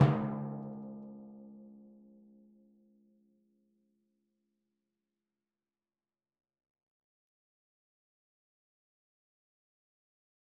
Timpani3_Hit_v4_rr2_Sum.wav